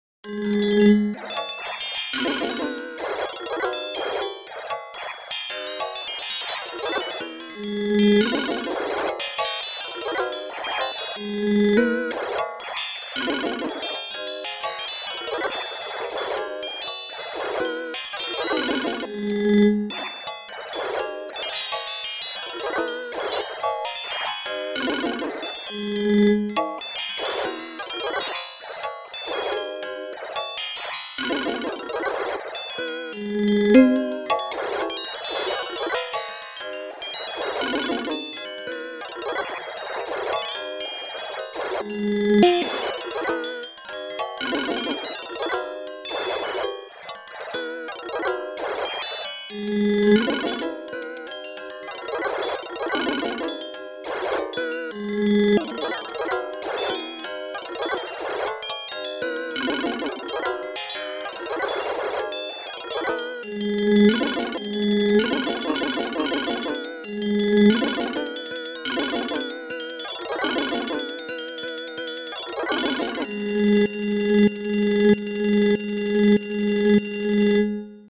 Four machine performances
These four recordings were all created using the Assistant Performer alone, without a live performer.
3. speed twice as fast as notated in the score, minimum ornament chord duration set to 1 millisecond.